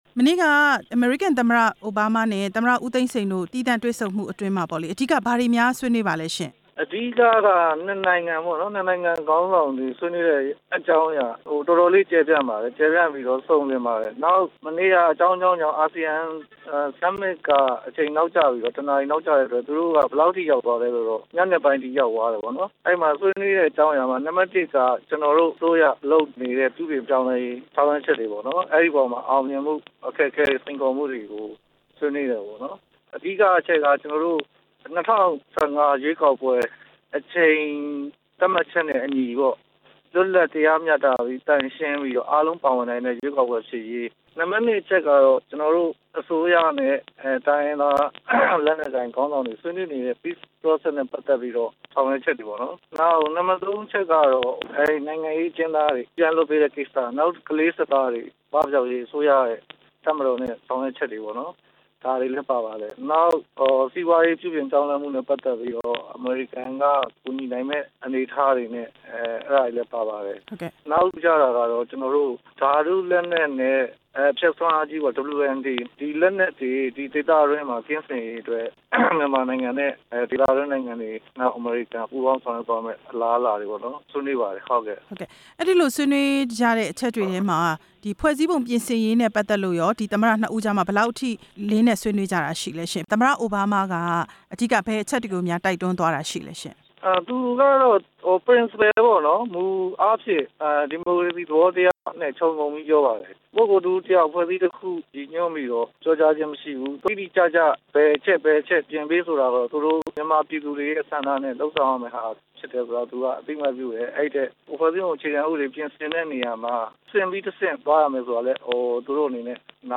အကြီးတန်းအရာရှိတစ်ဦးကို ဆက်သွယ်မေးမြန်းချက်